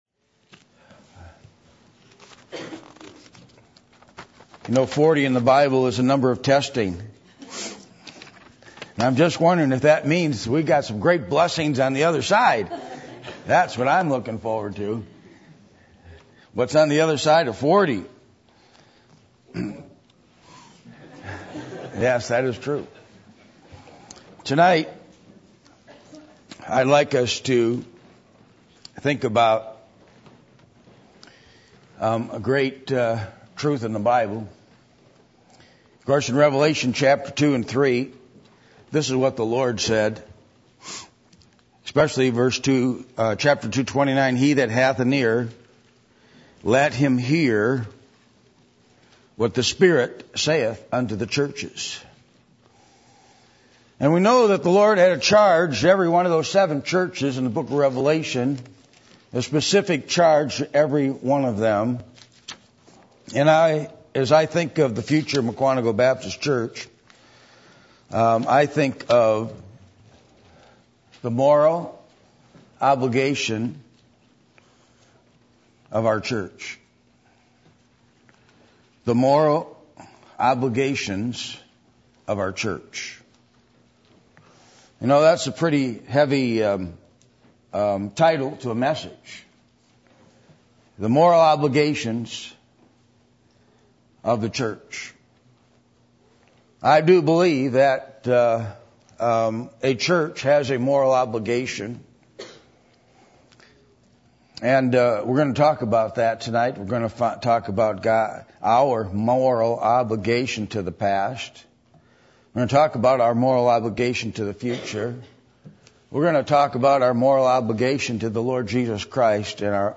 Revelation 2:29 Service Type: Sunday Evening %todo_render% « The Gospel